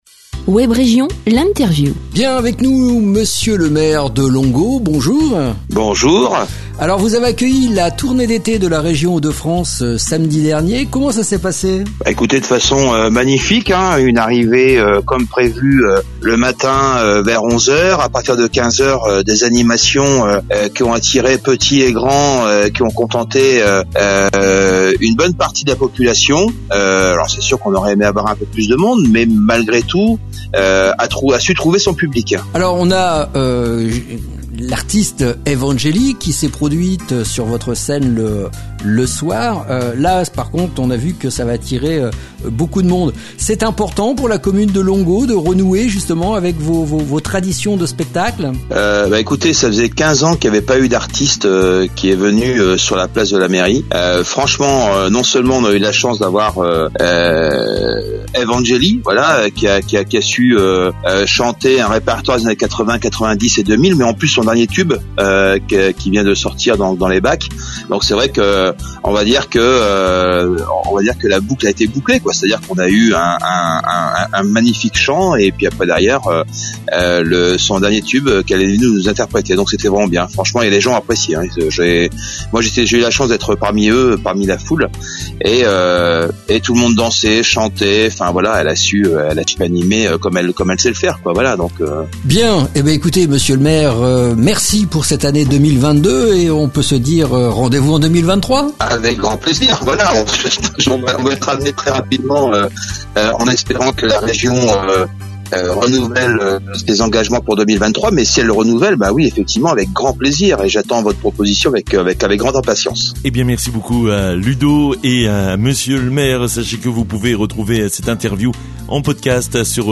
ITW MR PASCAL OURDOUILLÉ MAIRE DE LONGUEAU